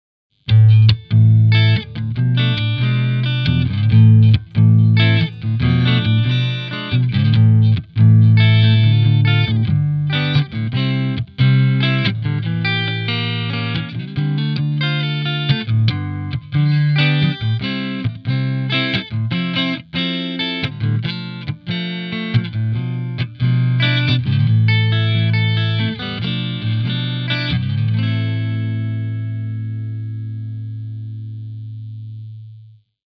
Gespielt habe ich je nach Sample eine Les Paul Style Gitarre, eine Strat oder eine Powerstrat. Als Box kam eine Marshall 4x12 mit Greenbacks zum Einsatz und abgenommen wurde mit einem SM57 direkt in den PC.
Clean
clean_07.mp3